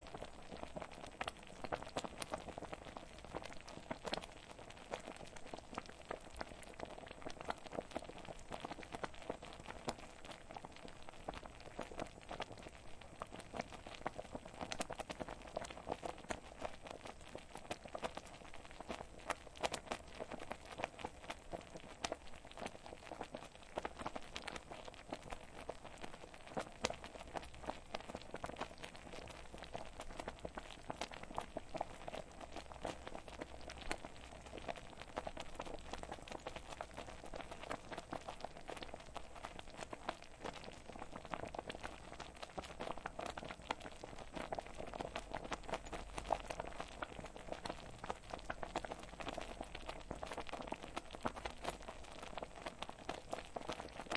Звуки кипящей каши в кастрюле